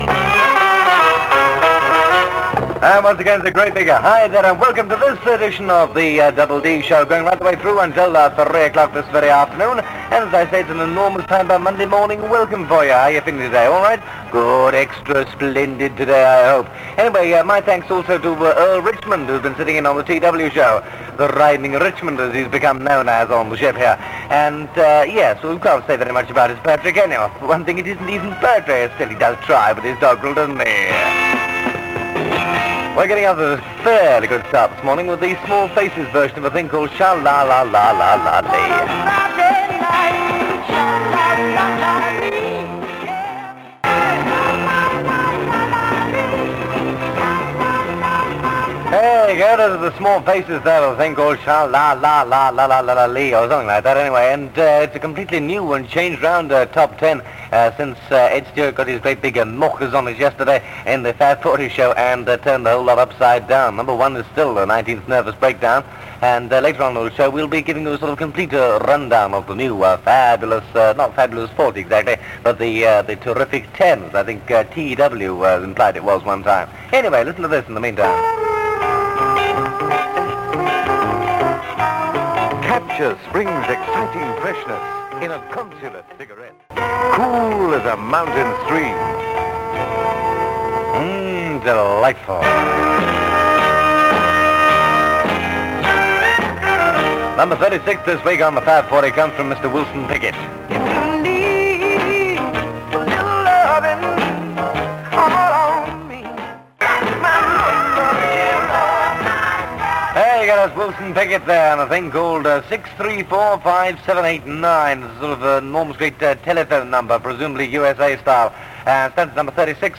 He had an instantly recognisable staccato delivery. His shows were peppered with unique catch-phrases mixed with imagination and humour.
The theme tune is Pinball by The Van Doren Hawksworth Collection.